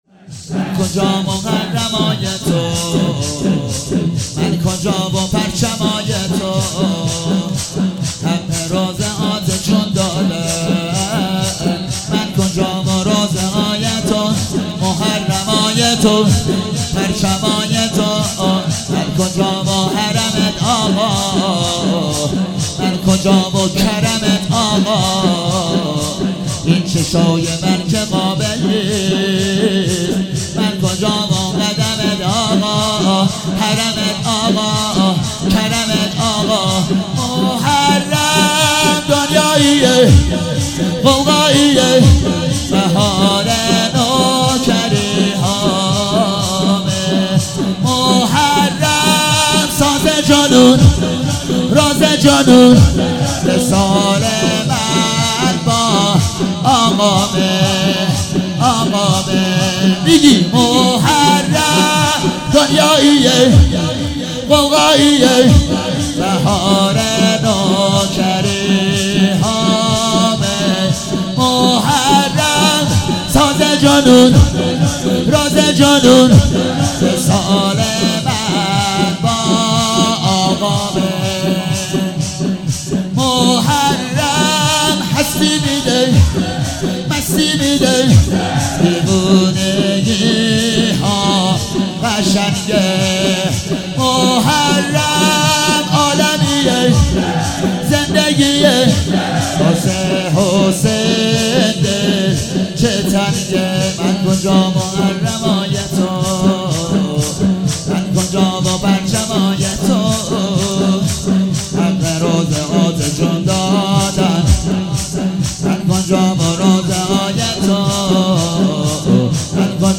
شب هفتم محرم 96 - شور - من کجا محرمای تو